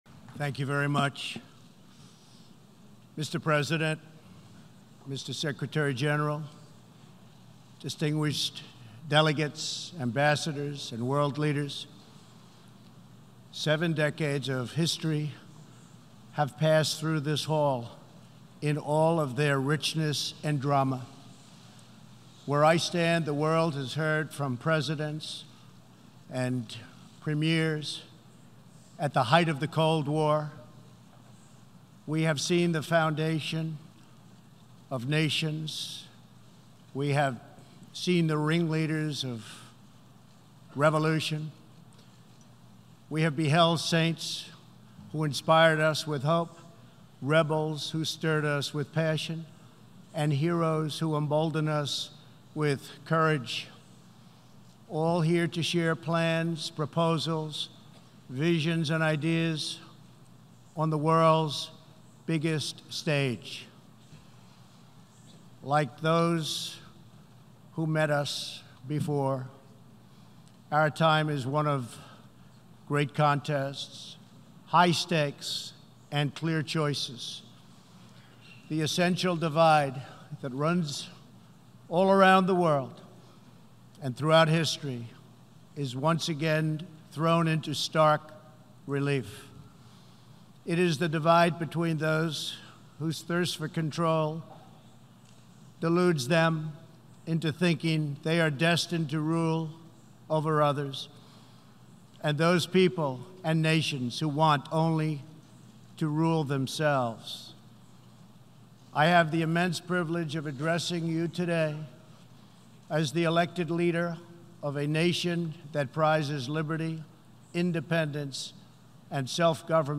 September 24, 2019: Remarks at the United Nations General Assembly
president_trump_addresses_the_74th_session_of_the_united_nations_general_assembly.mp3